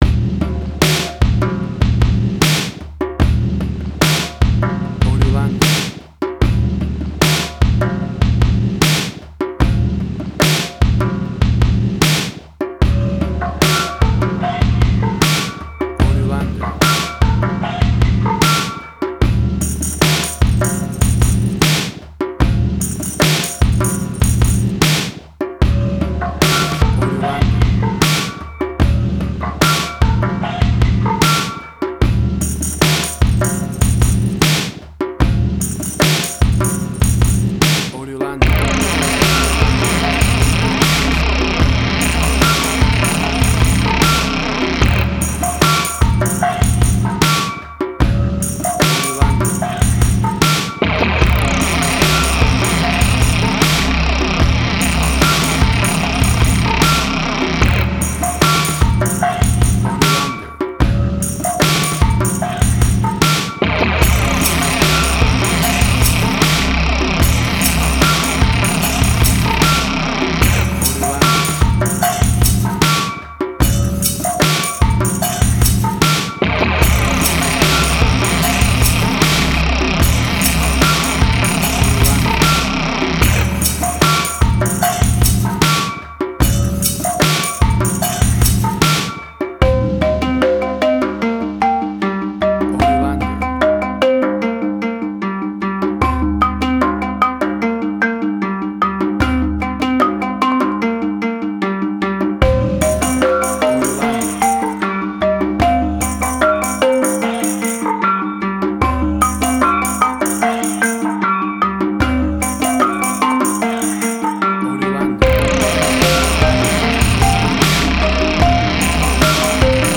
Middle Eastern Fusion.
Tempo (BPM): 75